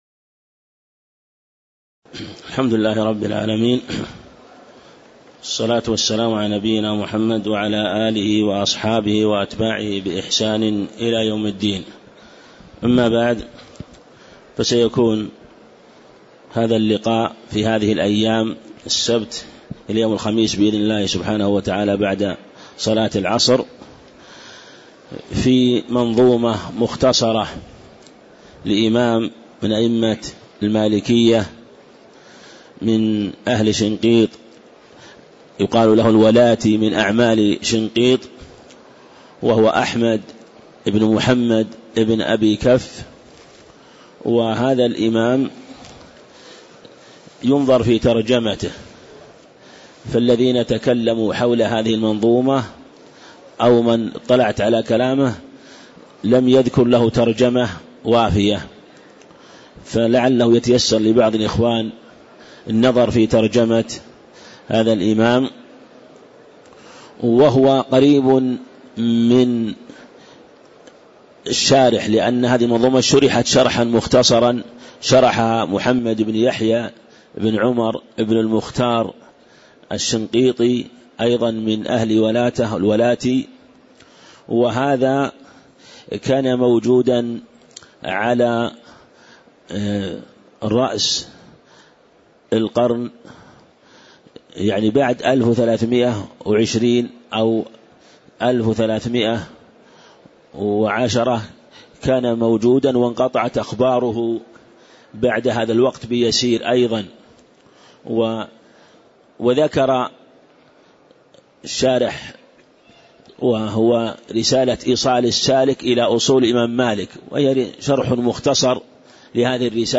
تاريخ النشر ٣ جمادى الآخرة ١٤٣٧ هـ المكان: المسجد النبوي الشيخ